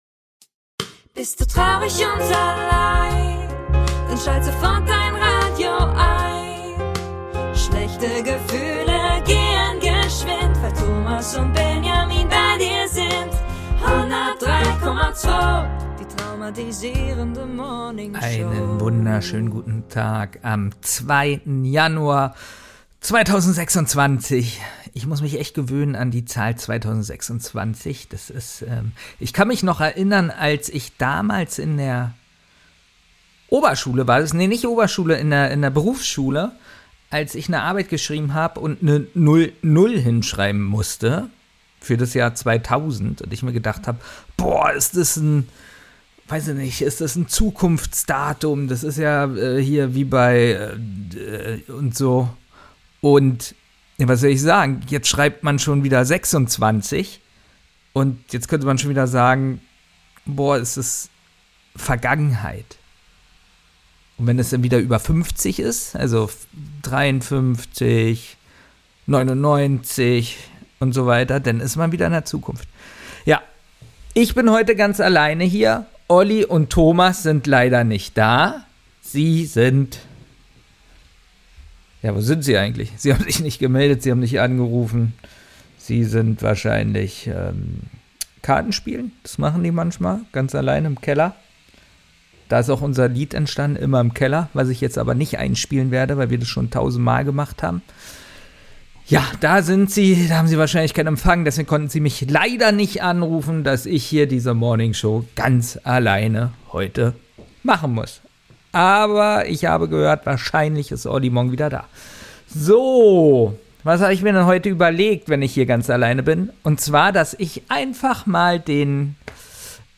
Da fängt er an, euch einfach mal seine News - Seite vorzulesen...